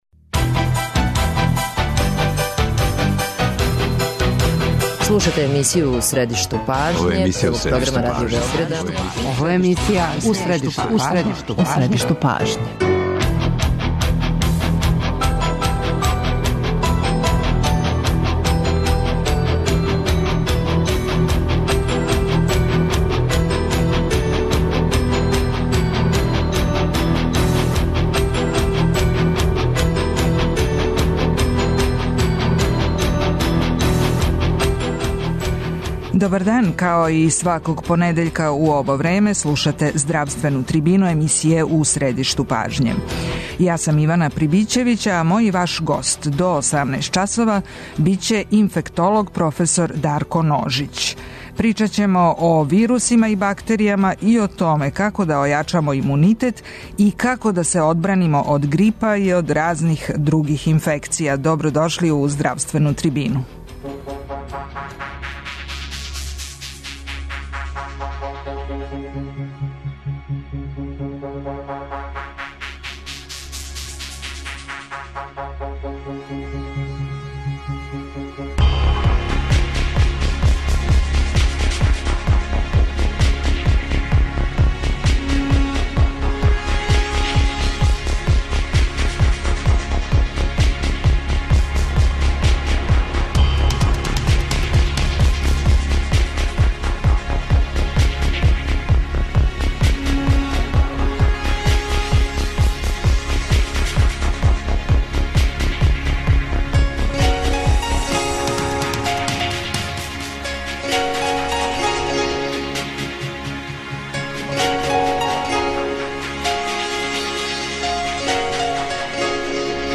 доноси интервју